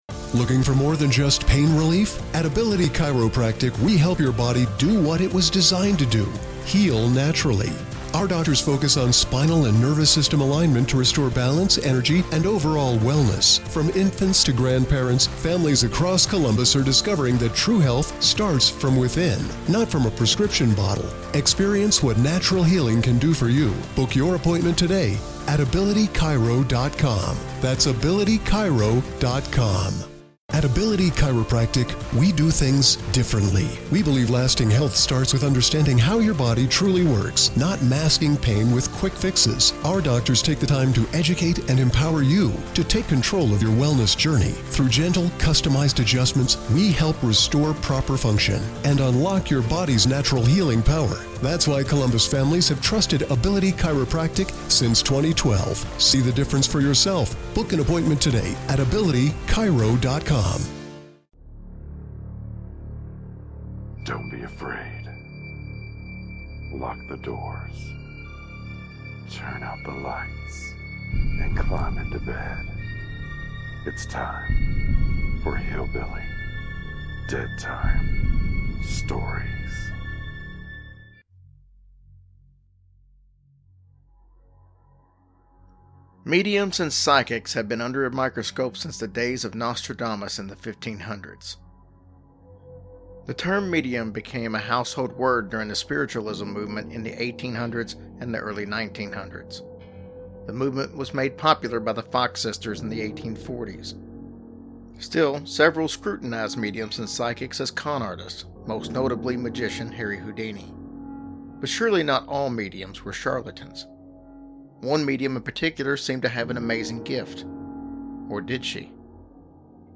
Introduction voice over
Closing song